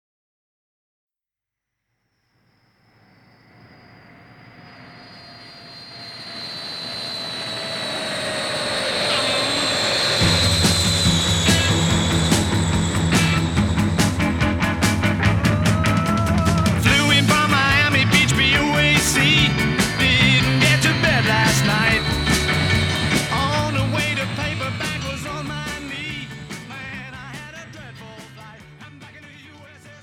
zpěv, basová kytara, kytara, klavír, bicí
sólová kytara